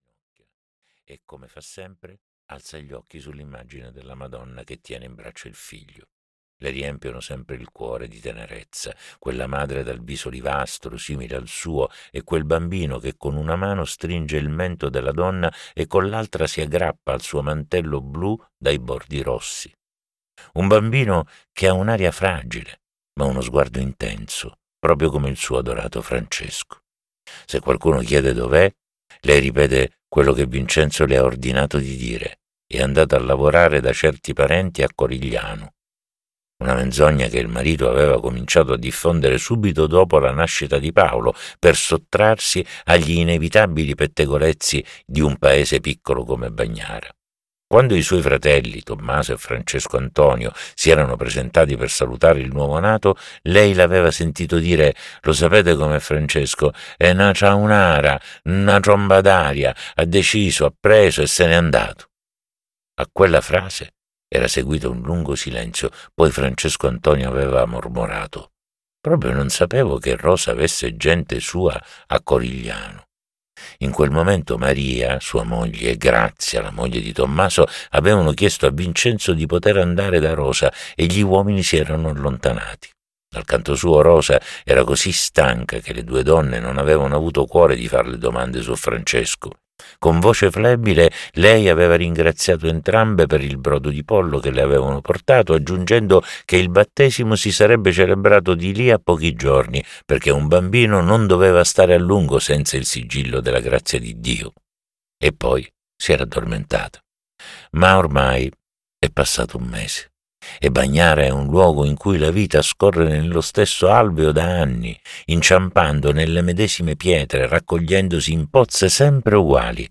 "L'alba dei Leoni" di Stefania Auci - Audiolibro digitale - AUDIOLIBRI LIQUIDI - Il Libraio
• Letto da: Ninni Bruschetta